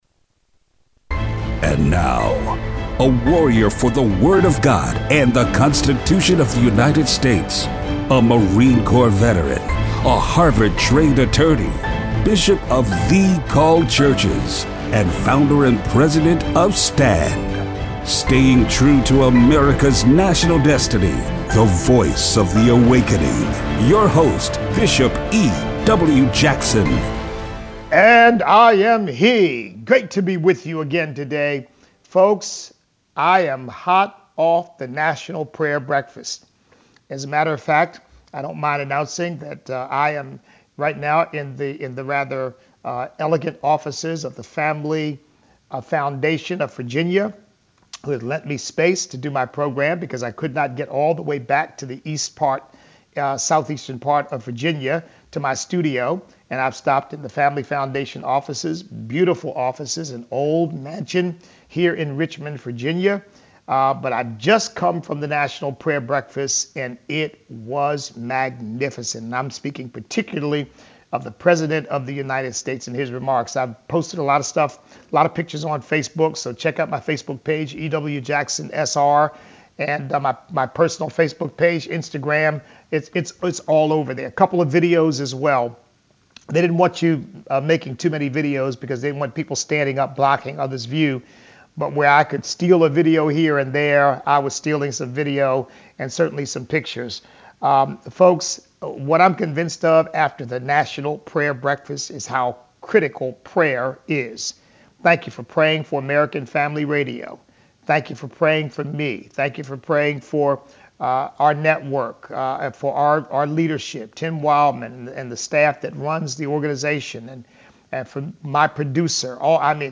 Listener call-in.